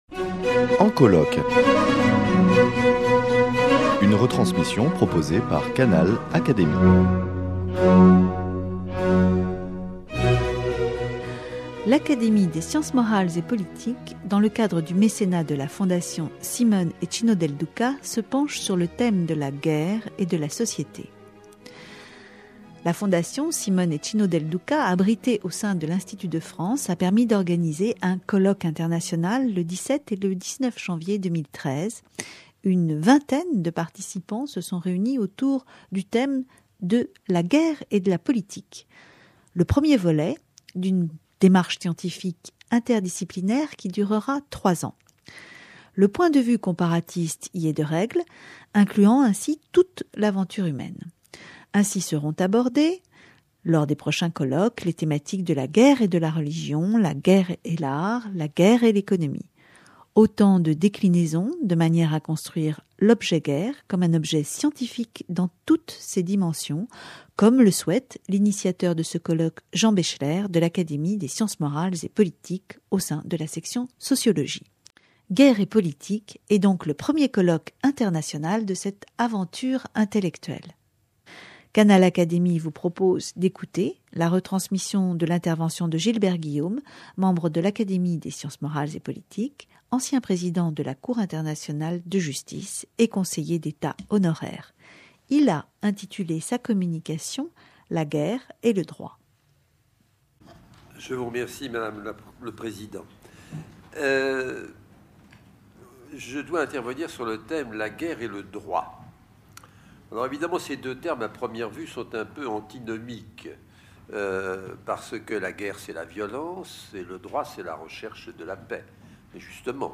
La Fondation Simone et Cino Del Duca, abritée au sein de l’Institut de France, a permis d’organiser ce colloque international le 17 et 19 janvier 2013.